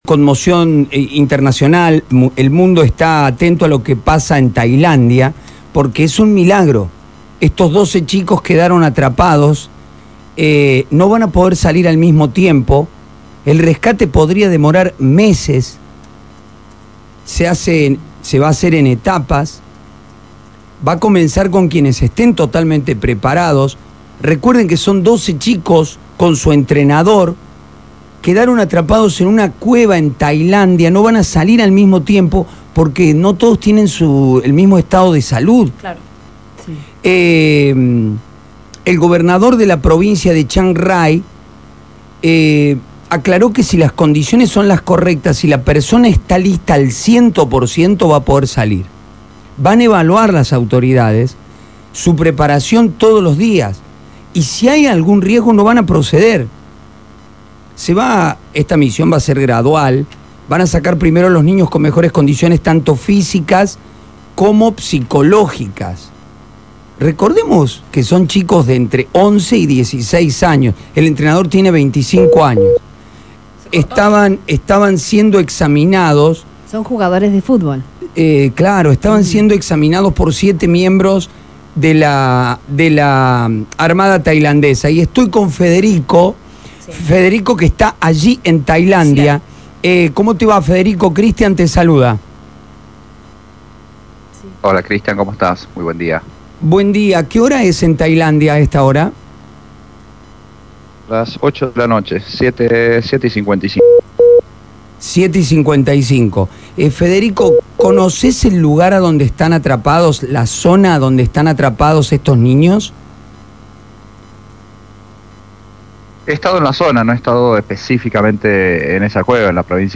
corresponsal espontáneo para Más Radio.